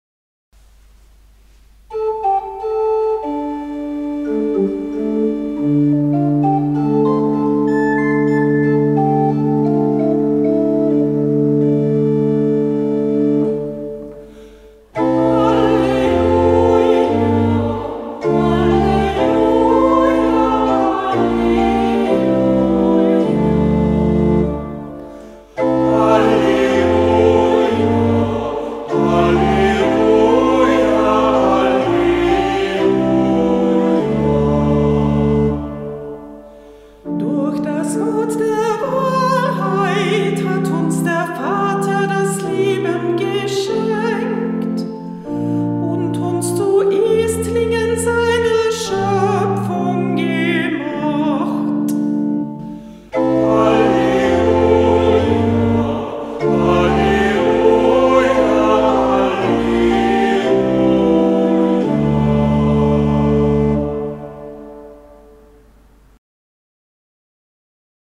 Ruf vor dem Evangelium - September 2024
Kantorin der Verse